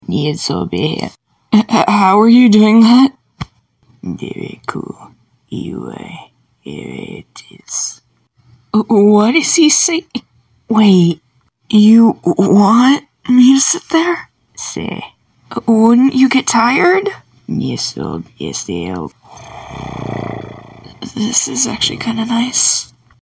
Both voices are mine and Blot's is done with no website reversal! All my voice!